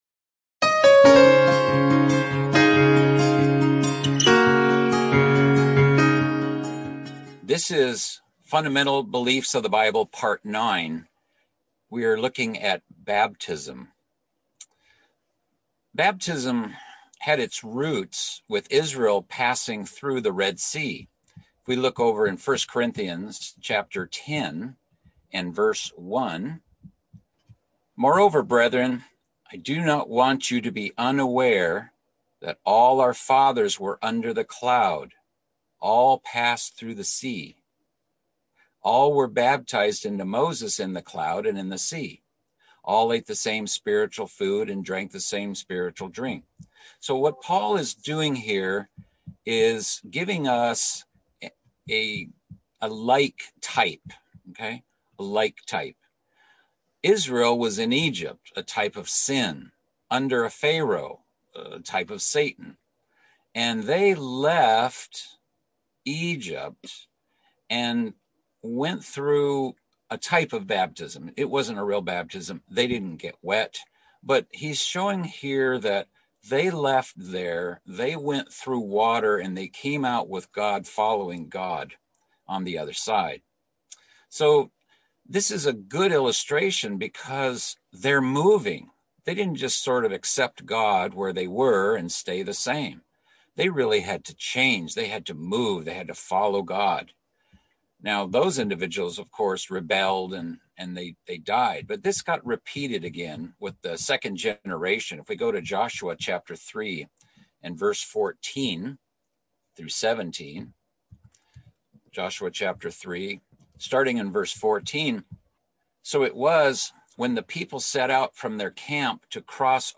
Fundamental Biblical Beliefs - Bible Study - Part 9 - Baptism